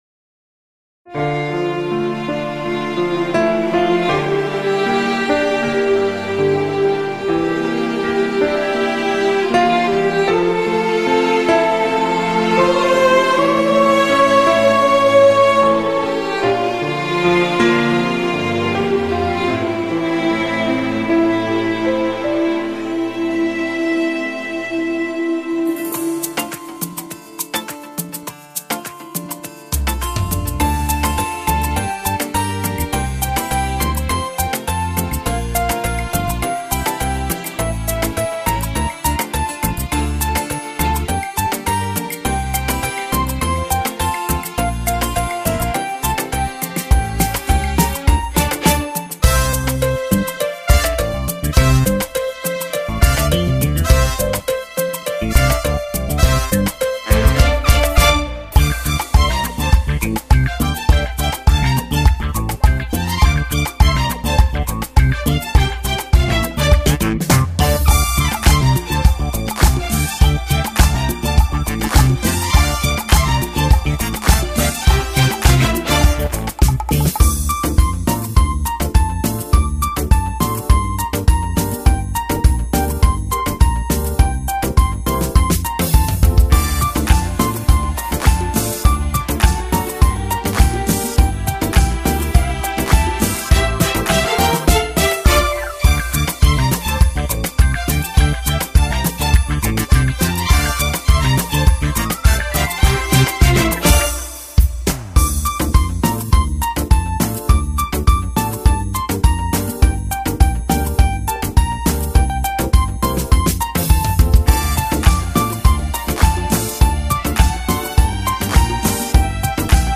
بدون کلام Music Only